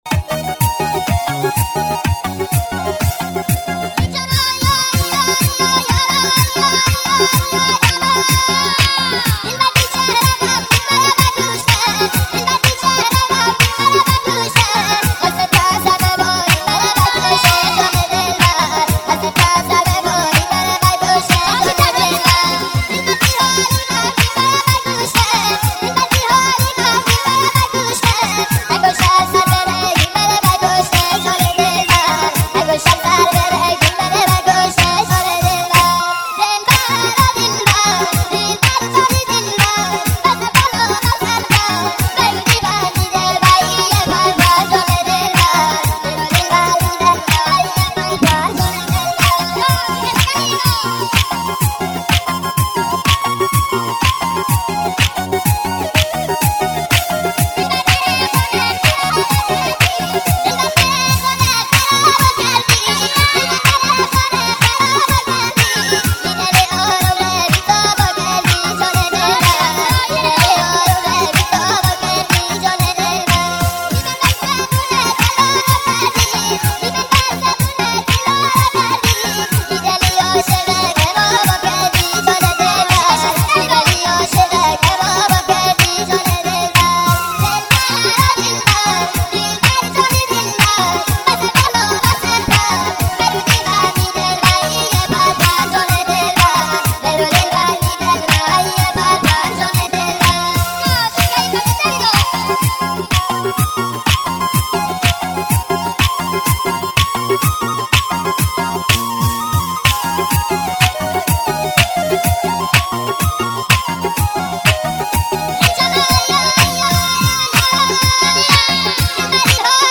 با صدای بچه نازک شده عروسکی
آهنگ مازندرانی
ریمیکس با صدای بچه